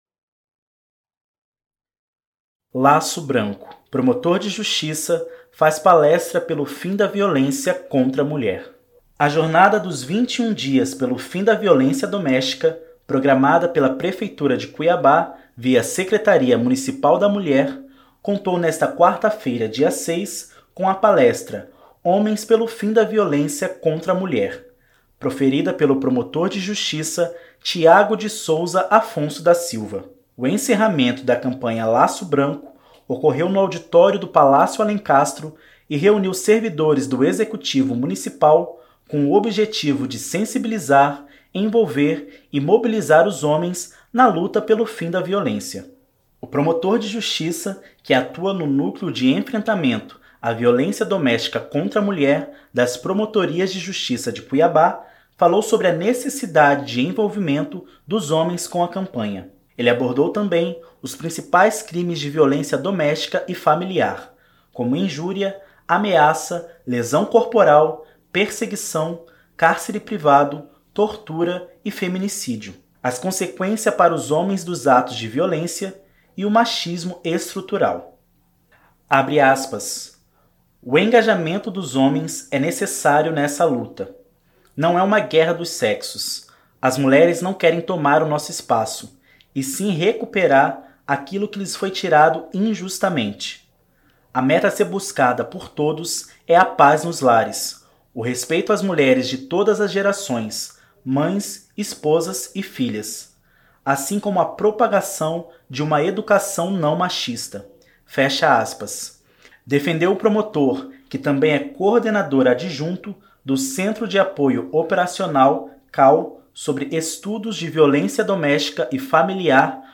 Promotor de Justiça faz palestra pelo fim da violência contra mulher